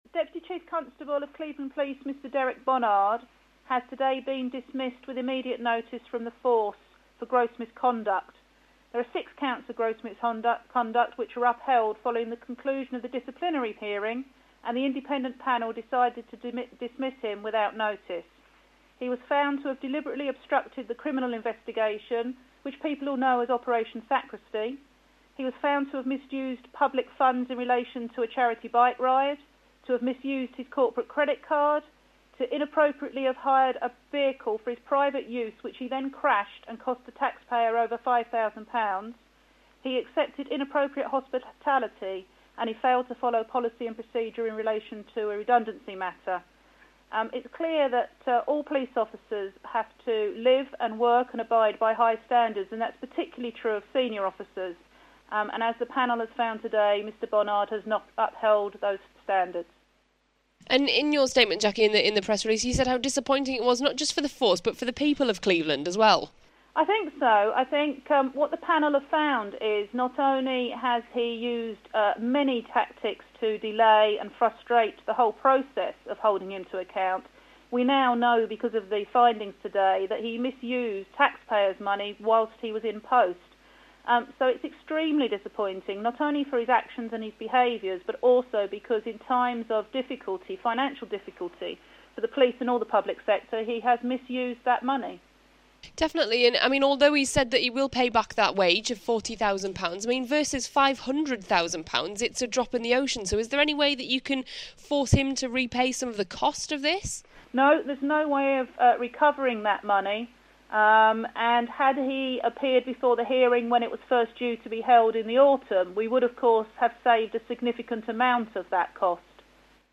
Derek Bonnard sacked - Jacqui Cheer interview